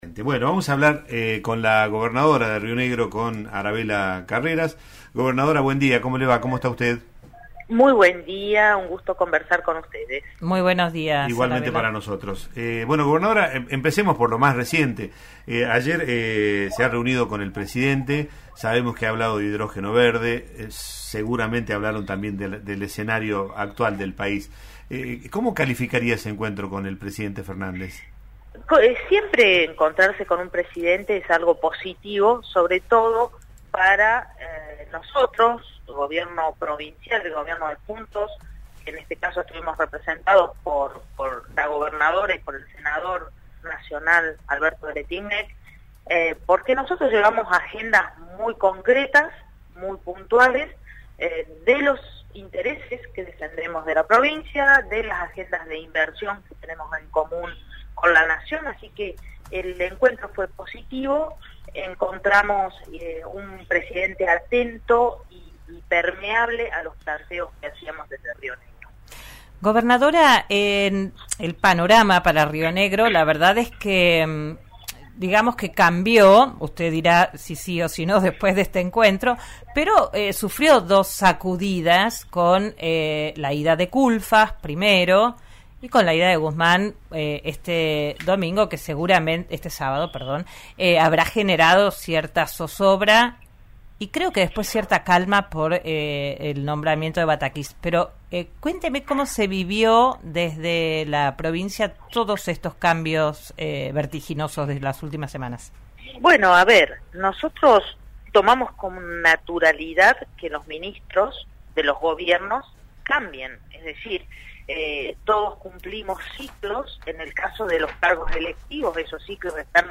La gobernadora dialogó con RN RADIO, confirmó que habrá elecciones separadas de las presidenciales y valoró la búsqueda de consensos permanente junto a Weretilneck.